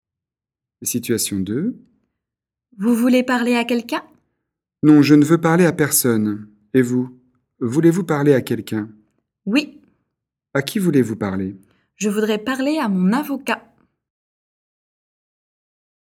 3 situations sous forme de dialogues, niveau intermédiaire (A2) pour exprimer la négation de quelqu'un ou quelque chose.